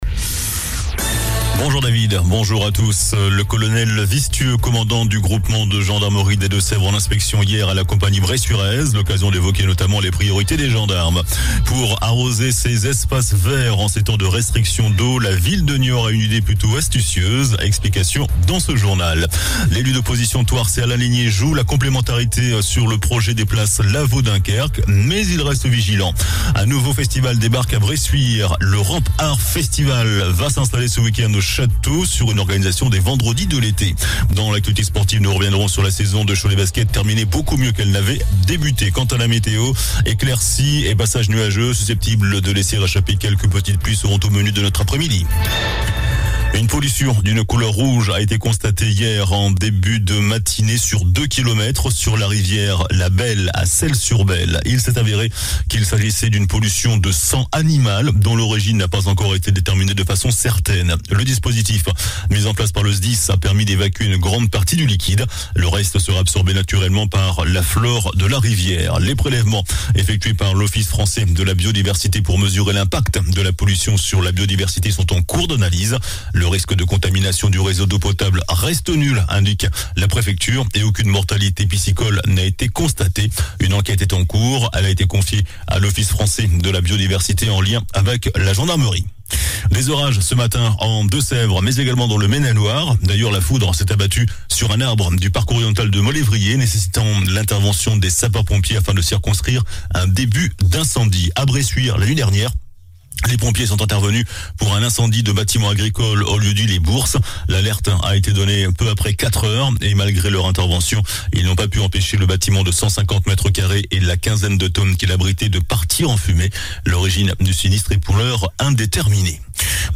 JOURNAL DU VENDREDI 03 JUIN ( MIDI )